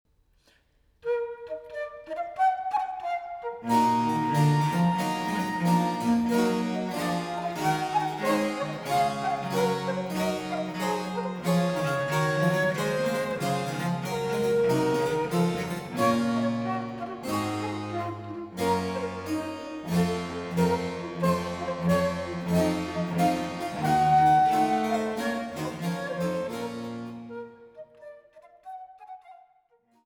Traversflöte
Cembalo
Gigue